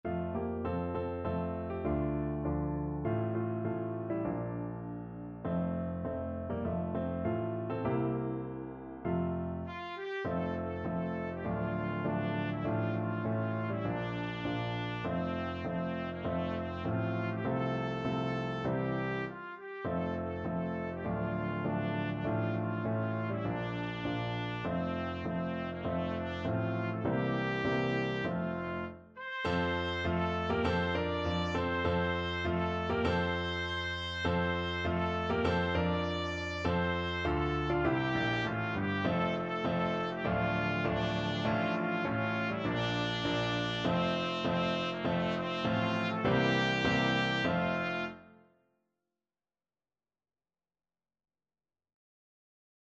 Trumpet version
4/4 (View more 4/4 Music)
Moderato
Traditional (View more Traditional Trumpet Music)